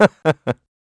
Riheet-Vox_Happy2_kr.wav